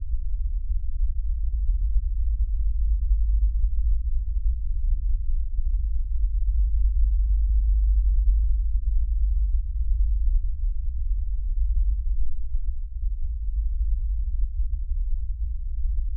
deepLoop.ogg